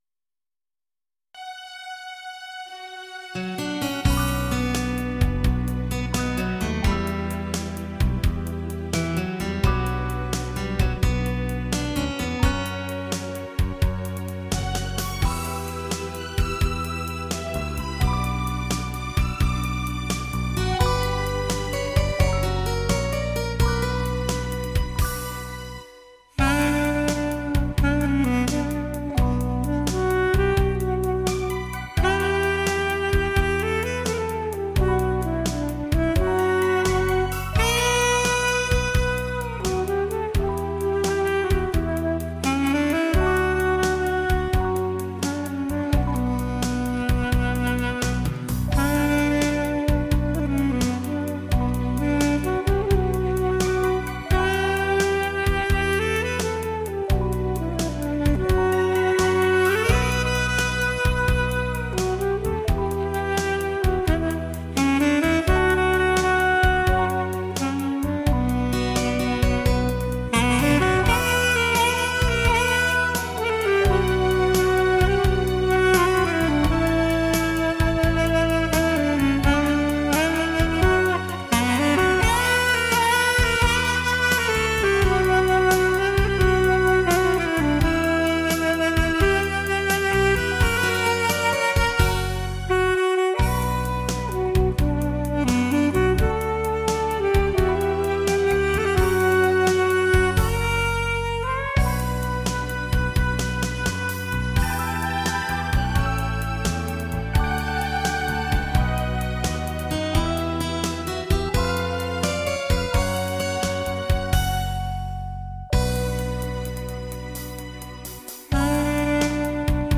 萨克斯演奏